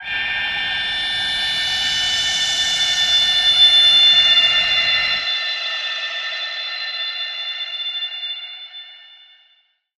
G_Crystal-A7-f.wav